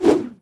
footswing2.ogg